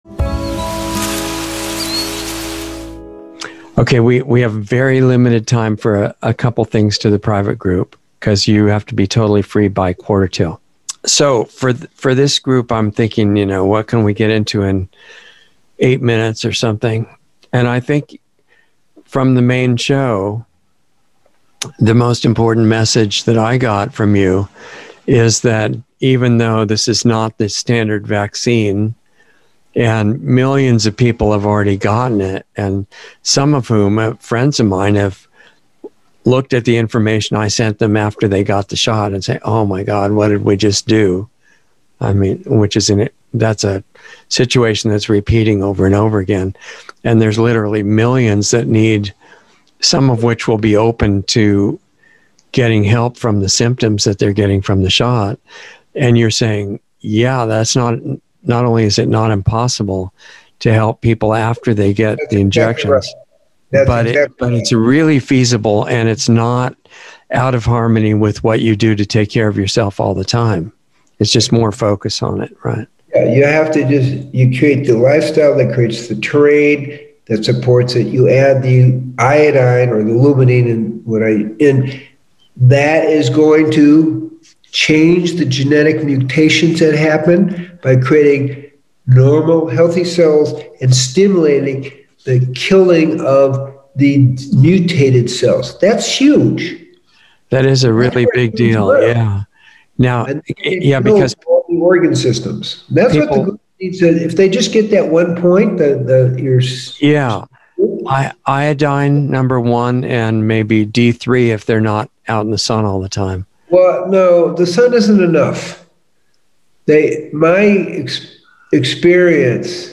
Insider Interview 4721